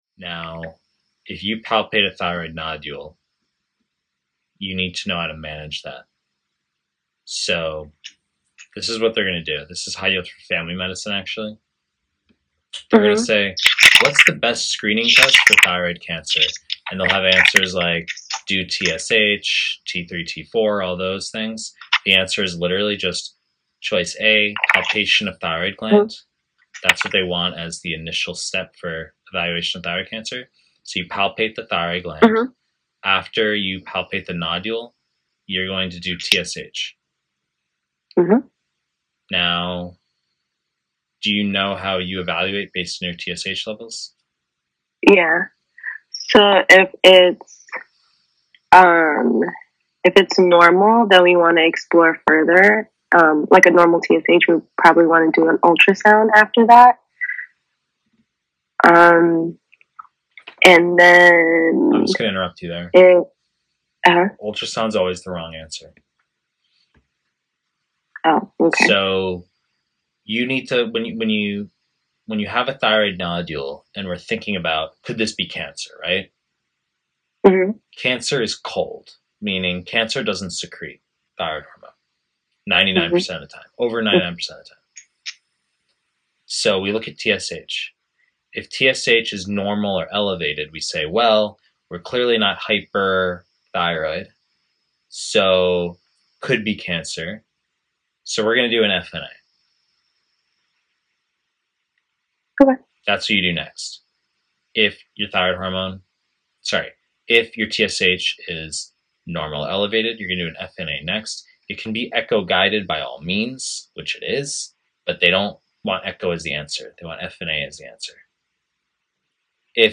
Pre-recorded lectures / Surgery